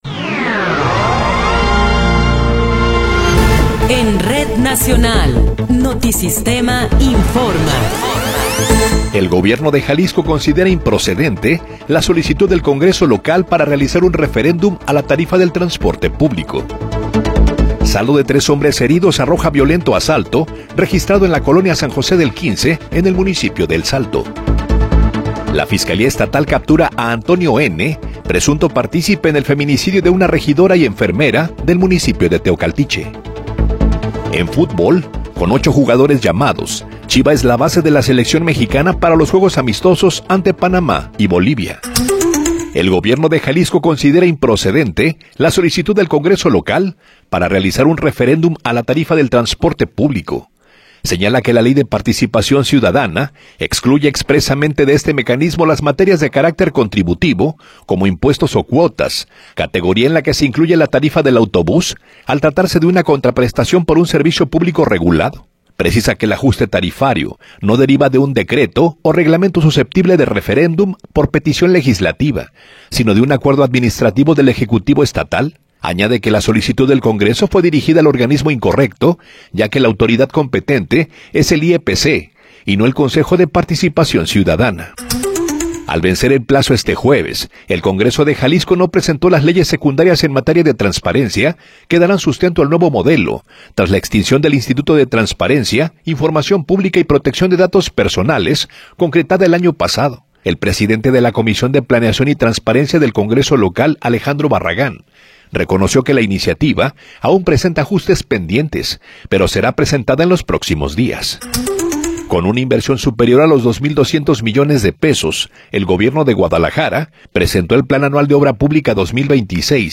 Noticiero 9 hrs. – 16 de Enero de 2026